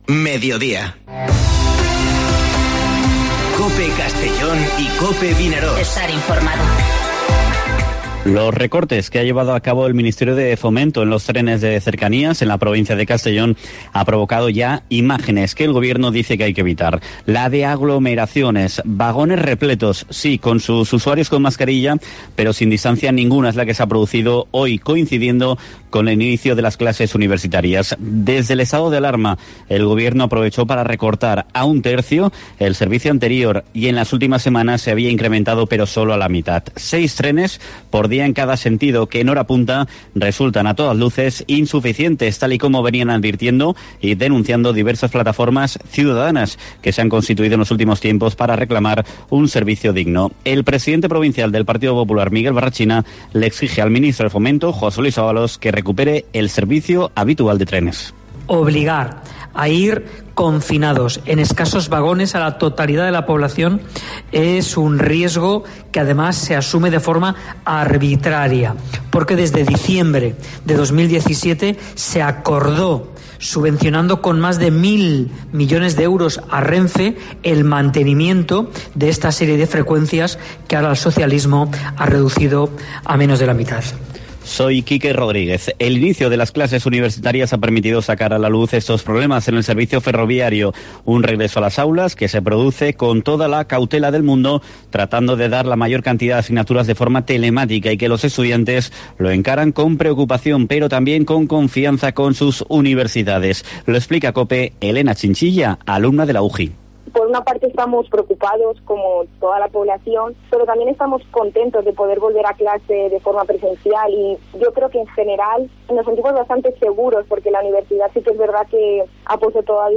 Informativo Mediodía COPE en la provincia de Castellón (28/08/2020)